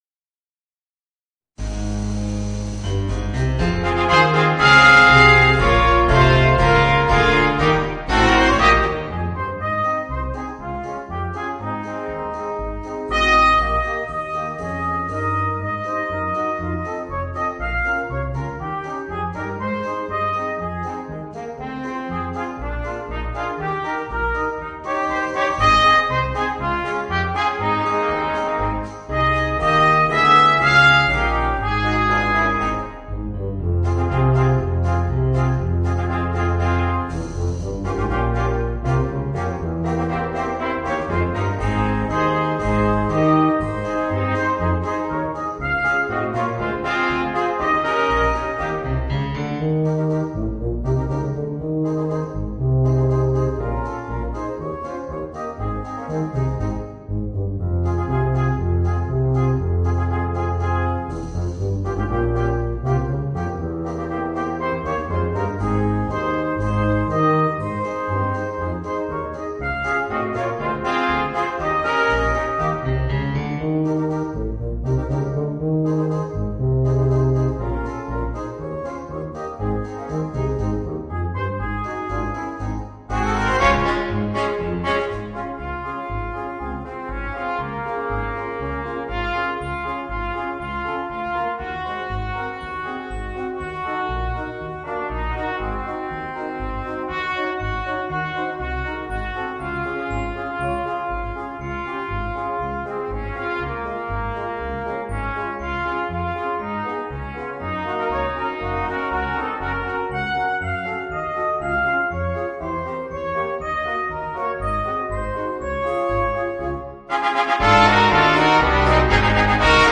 Voicing: 3 Trumpets and Trombone